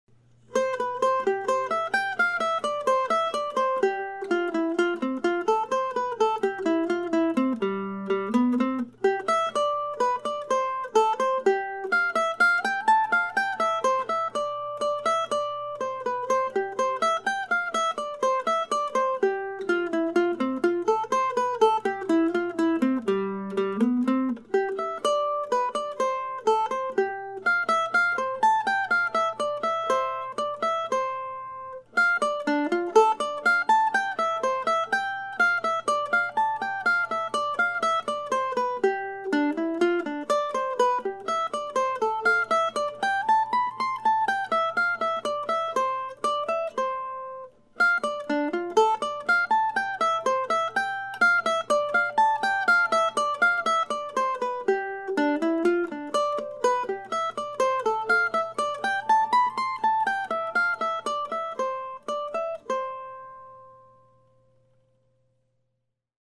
Early in 2016 I started writing short pieces modeled after the Divertimentos that James Oswald composed and published in the 1750s in London.
I've been playing them before or after Oswald's own pieces during my solo mandolin coffee house gigs this year and now my plan is to turn them into a small book that I intend to have available at the Classical Mandolin Society of America annual convention in Valley Forge, early next month.